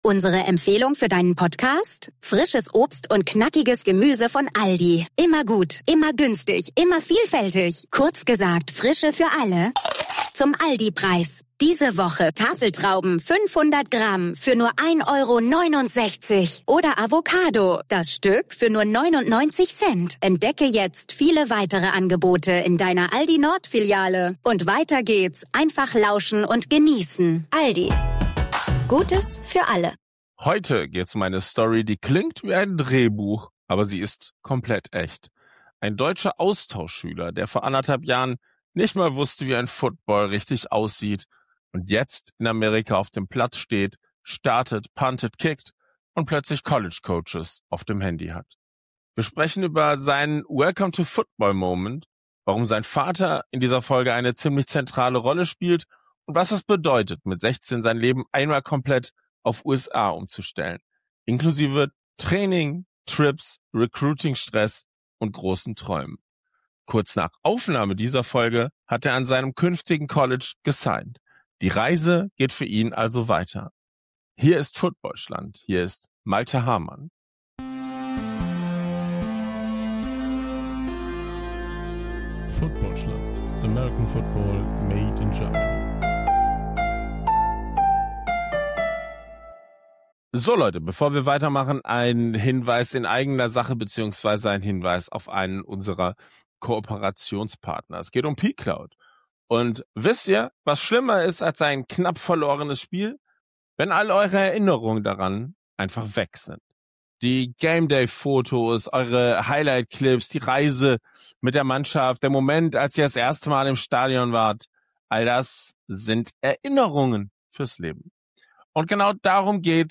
Ein Gespräch über Mut, ...